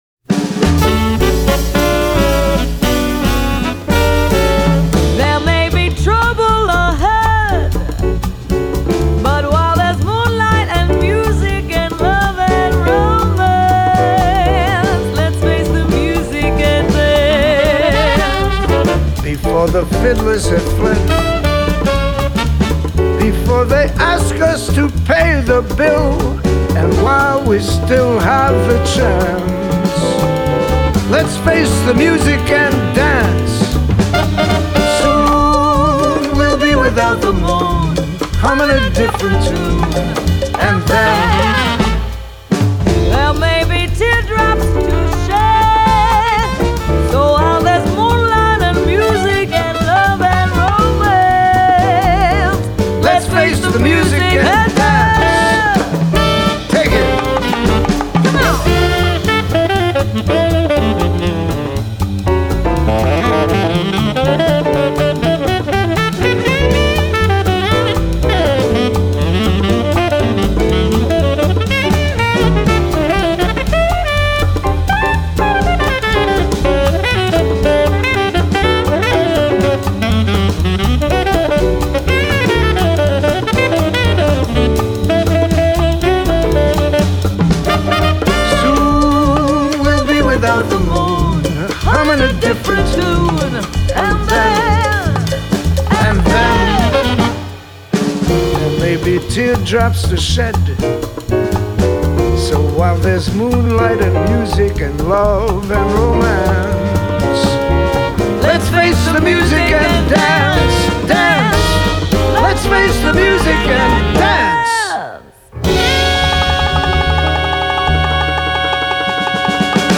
1936   Genre: Soundtrack   Artist